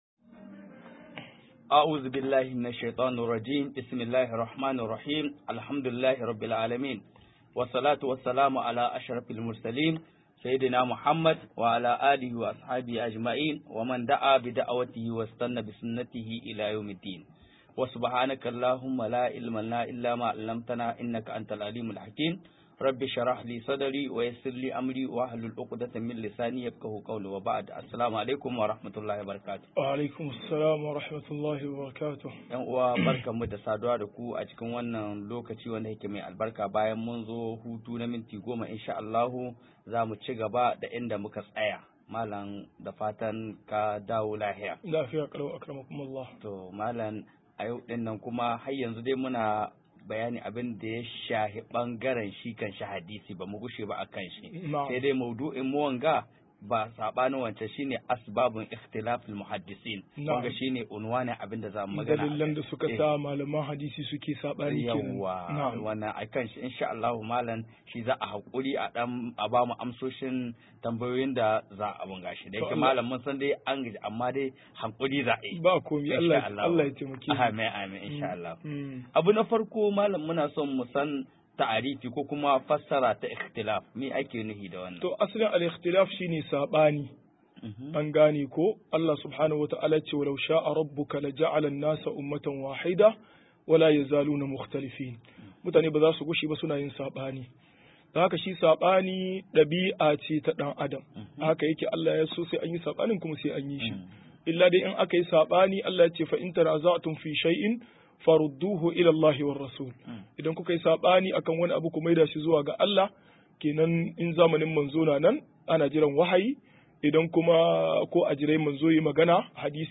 151-Dalillan Sabanin Maman Hadisi - MUHADARA